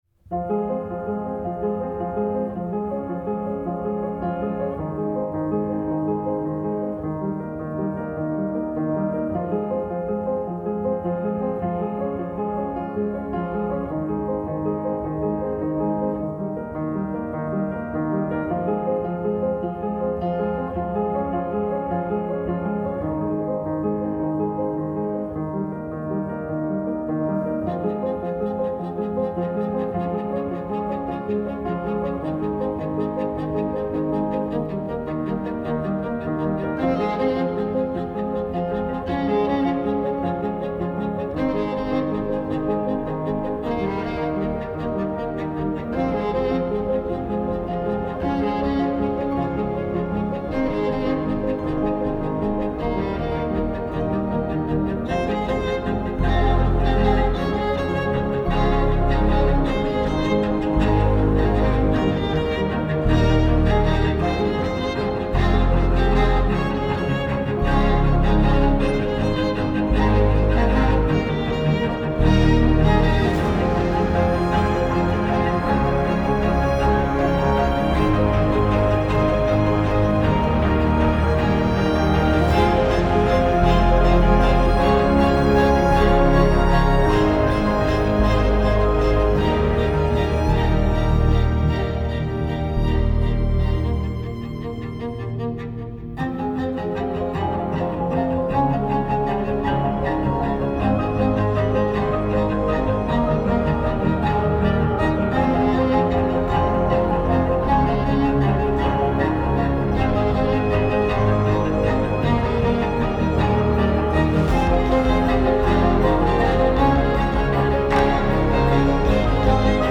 باشکوه , پیانو , موسیقی بی کلام , ویولن
موسیقی بی کلام ارکسترال